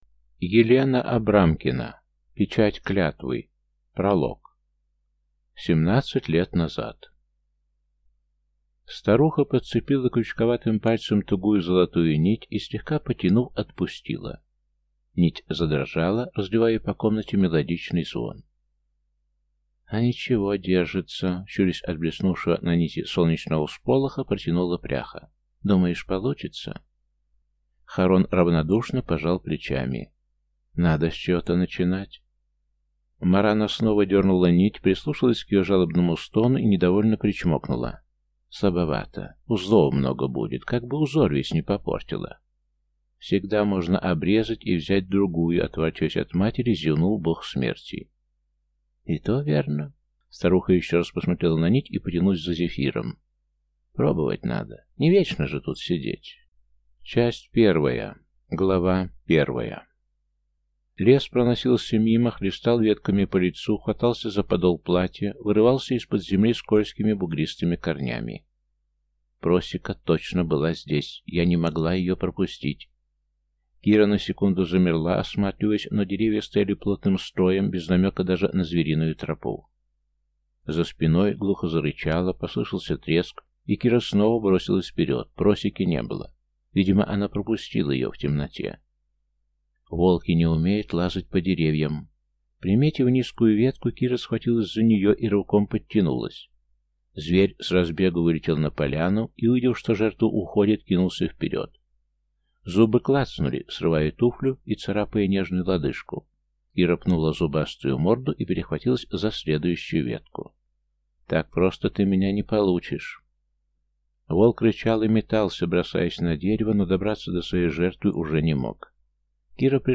Аудиокнига Печать клятвы | Библиотека аудиокниг